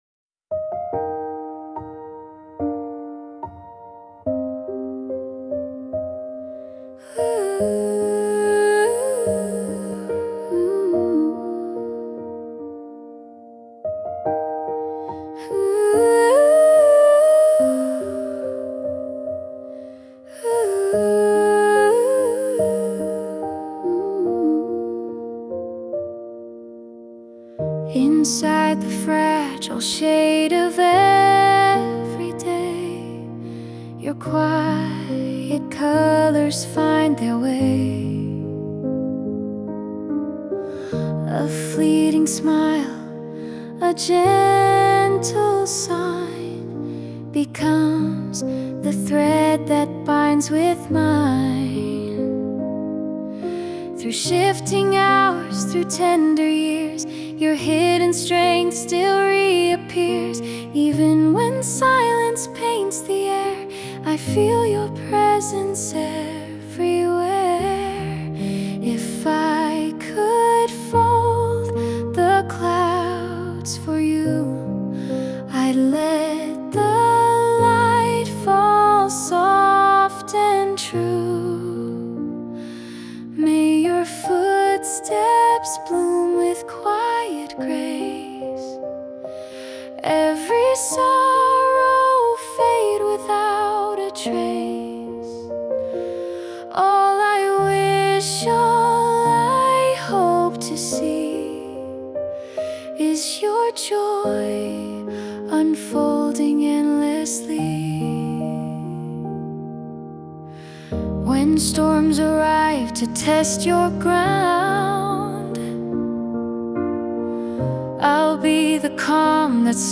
洋楽女性ボーカル著作権フリーBGM ボーカル
著作権フリーオリジナルBGMです。
女性ボーカル（洋楽・英語）曲です。
静かに、大切な人の幸せを祈るようなそんな優しい歌を目指しました✨